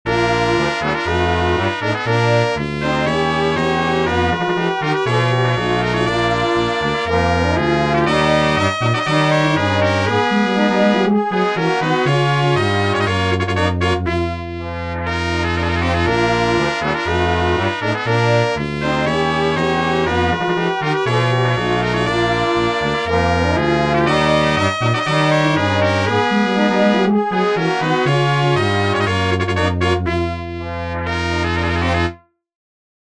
Short 120bpm loop in 18edo
18edo_demo_2.mp3